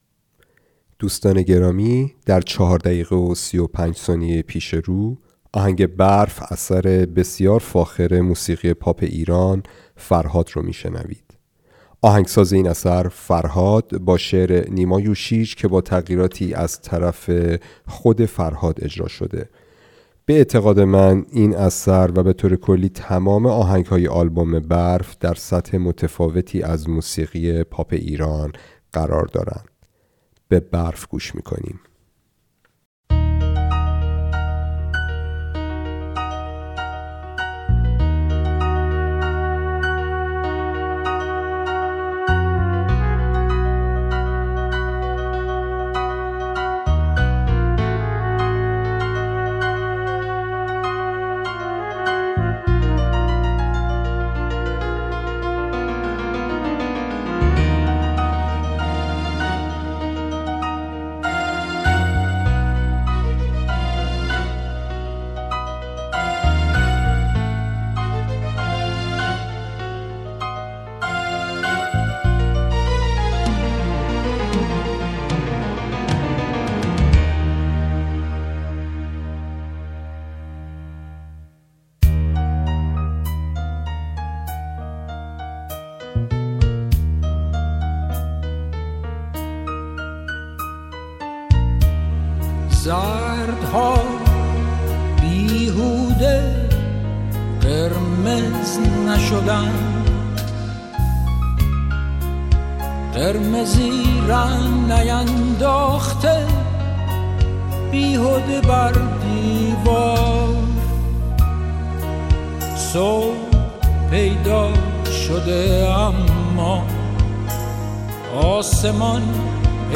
🎶 موسیقی بی‌کلام
🔹 سبک: موسیقی احساسی، ملودی‌های مینیمال و تأثیرگذار
🔹 فضای موسیقی: آرام، زمستانی، تأمل‌برانگیز